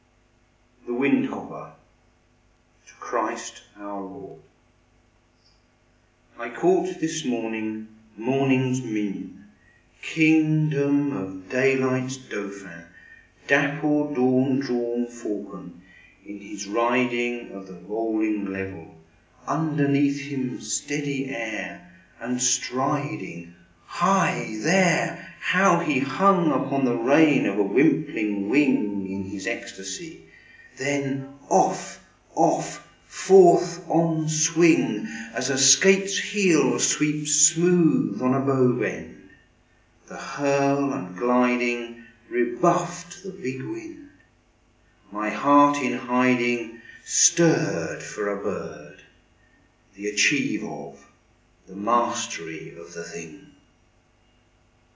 audio version of the poem.